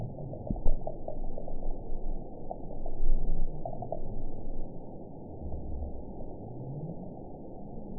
event 922139 date 12/27/24 time 06:38:15 GMT (5 months, 3 weeks ago) score 9.57 location TSS-AB06 detected by nrw target species NRW annotations +NRW Spectrogram: Frequency (kHz) vs. Time (s) audio not available .wav